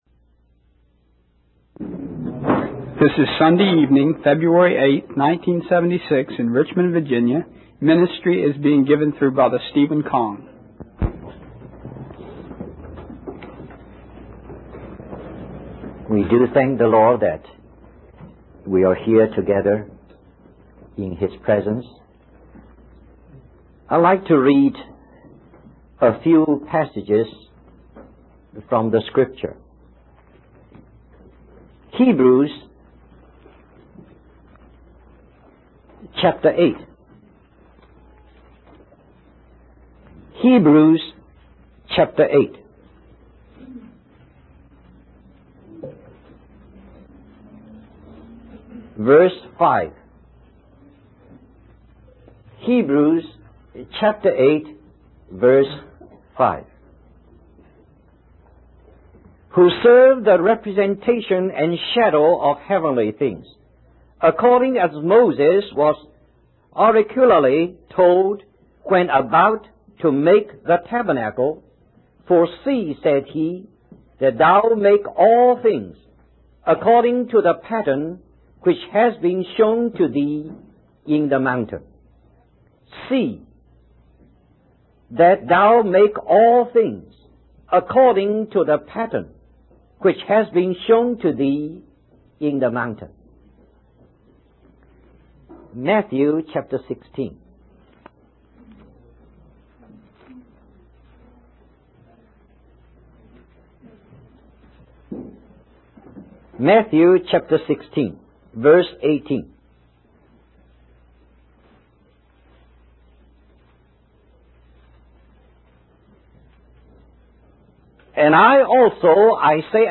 In this sermon, the speaker emphasizes the need for humility before God and the importance of seeking more light from Him. He warns against the danger of becoming proud when we have some understanding of spiritual matters.